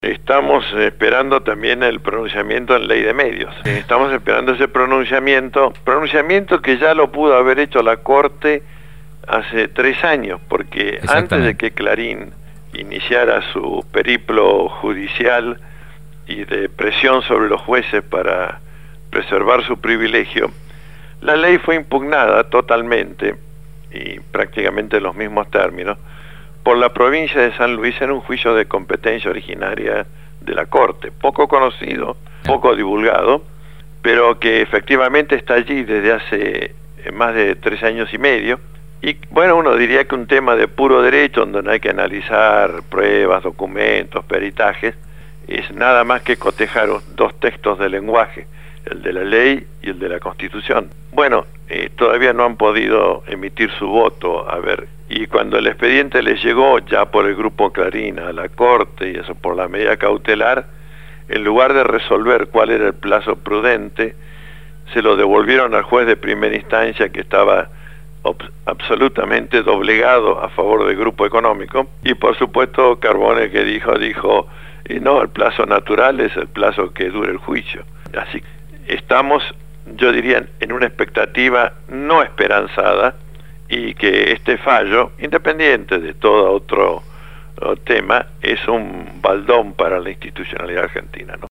En diálogo telefónico